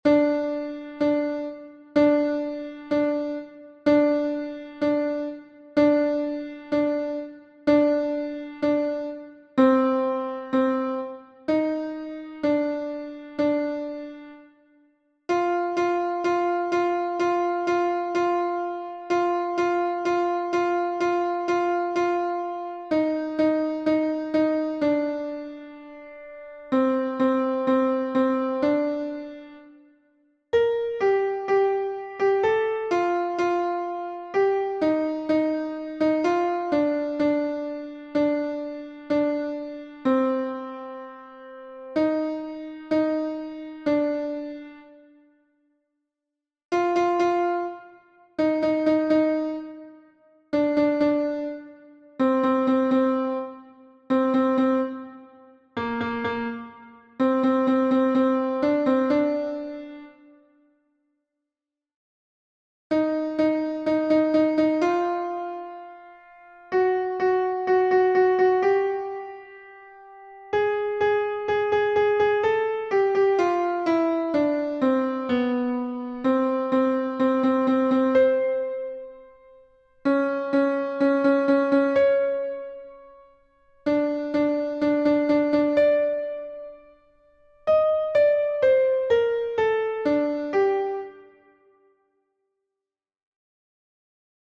LA_SOUPE_A_LA_SORCIERE_voix2